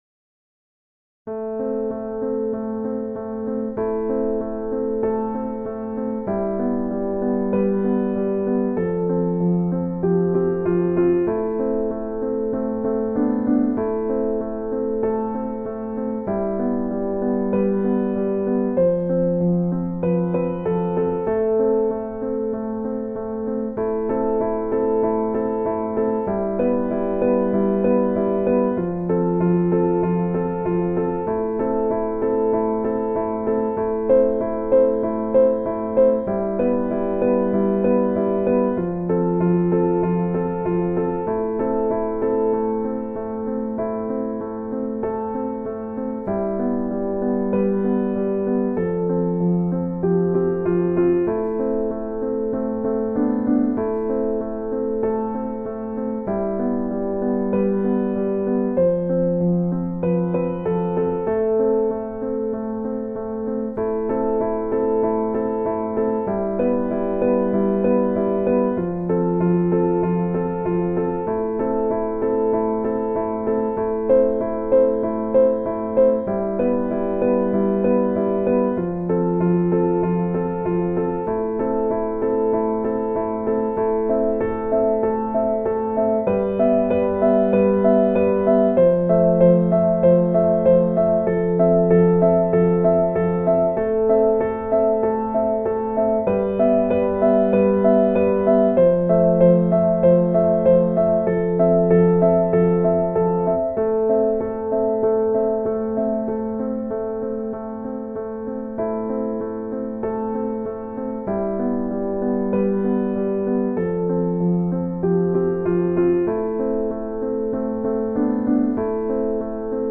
Instrumentation : Piano
Genre:  Spirituel